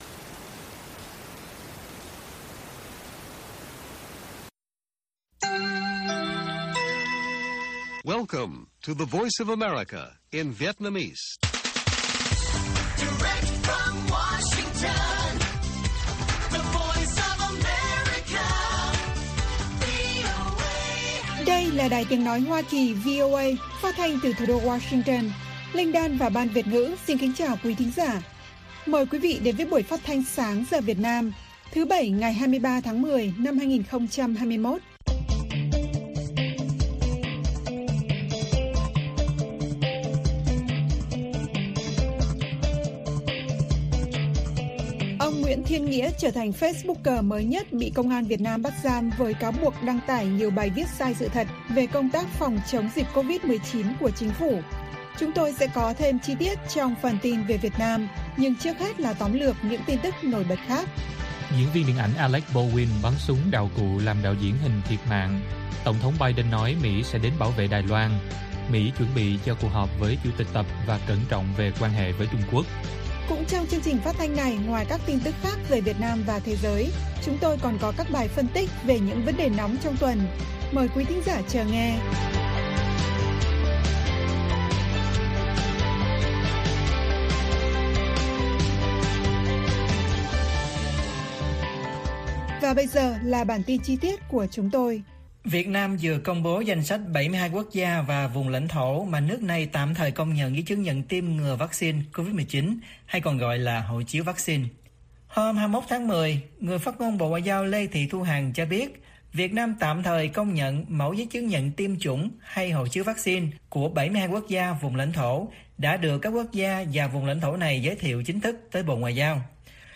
Bản tin VOA ngày 23/10/2021